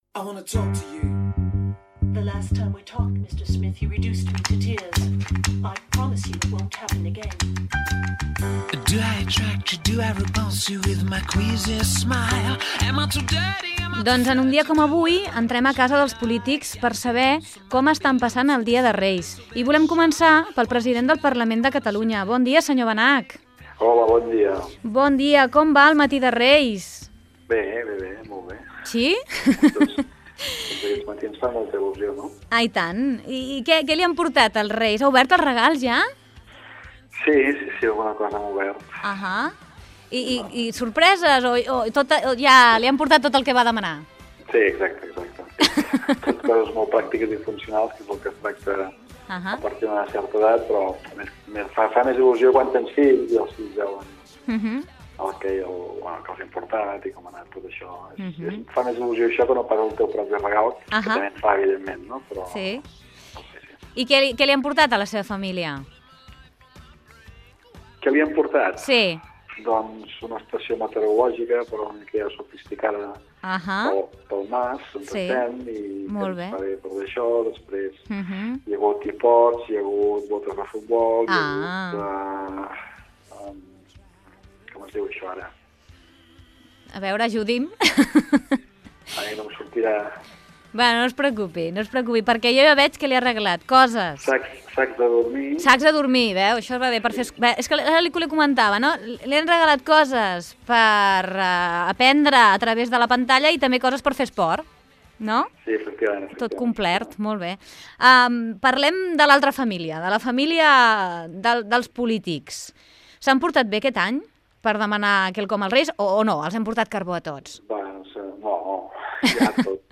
Fragment d'una entrevista al president del Parlament de Catalunya, Ernest Benach, per saber com passa el matí del dia de Reis
Infantil-juvenil